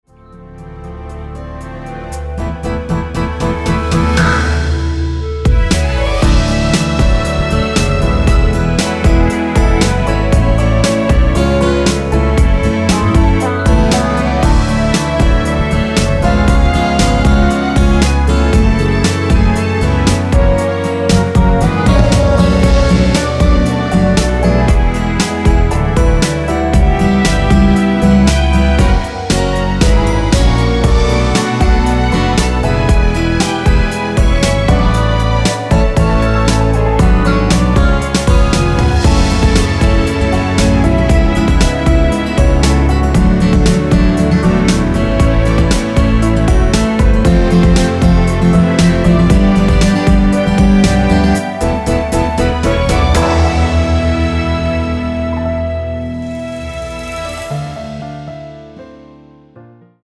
원키에서(-3)내린 (1절+후렴)으로 진행되는 멜로디 포함된 MR입니다.
Gbm
앞부분30초, 뒷부분30초씩 편집해서 올려 드리고 있습니다.